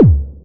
Index of /90_sSampleCDs/Club_Techno/Percussion/Kick
Kick_09.wav